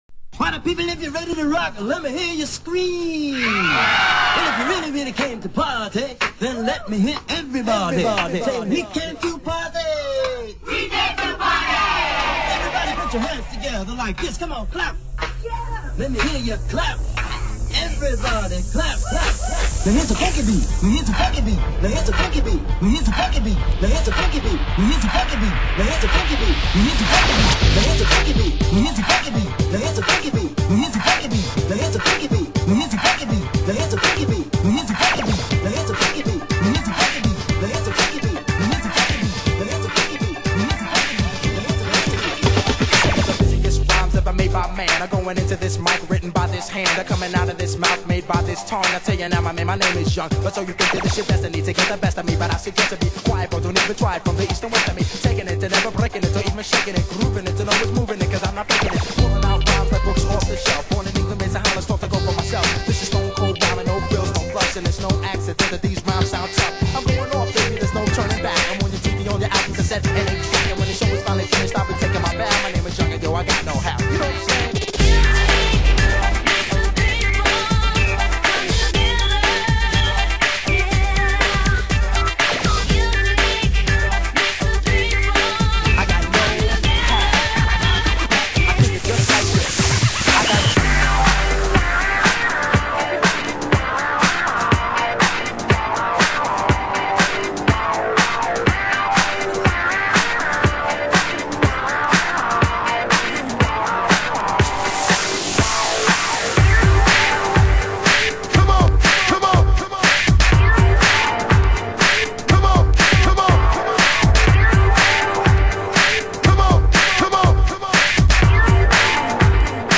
HOUSE MUSIC
盤質：A面に軽いスレ有/少しチリパチノイズ有